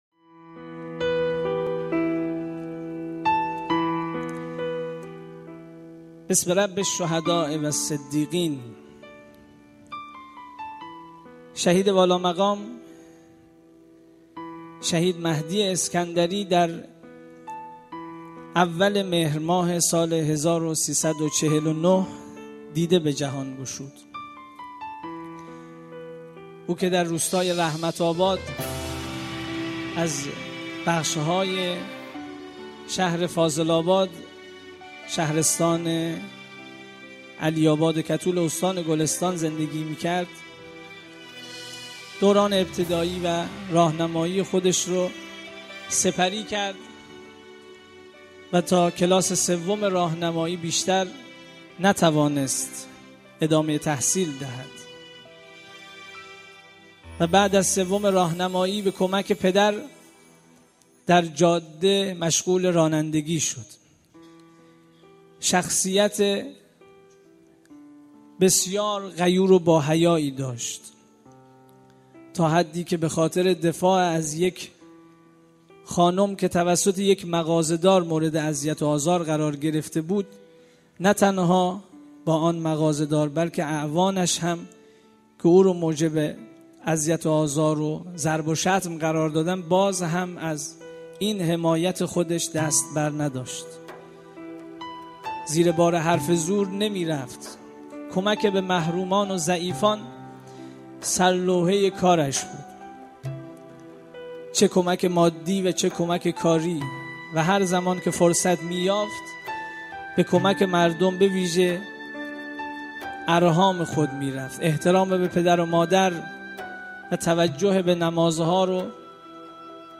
در این بخش می توانید فایل صوتی بخش های مختلف “یکصد و شصت و پنجمین کرسی تلاوت و تفسیر قرآن کریم” شهرستان علی آباد کتول که در تاریخ ۱۵/اسفند ماه/۱۳۹۶ برگزار شد را مشاهده و دریافت نمایید.